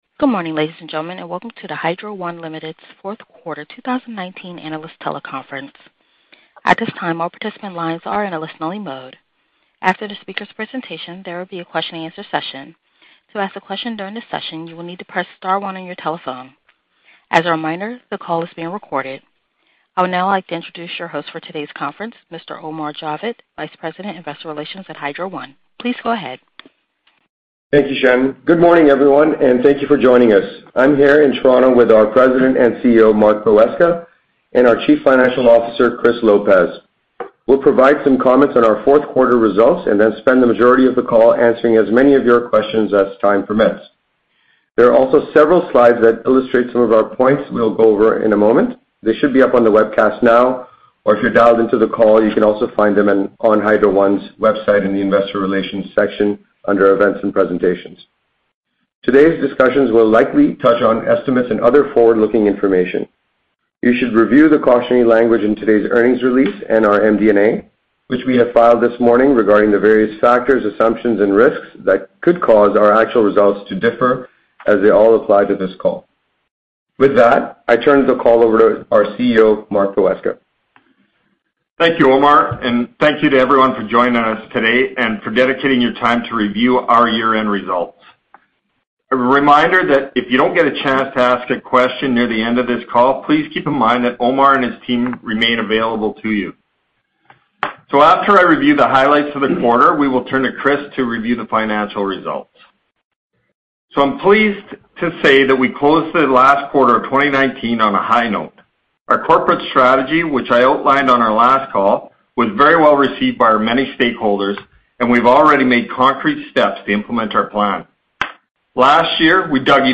Hydro One Limited 4Q19 Analyst Call Recording.mp3